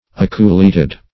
Aculeated \A*cu"le*a`ted\, a.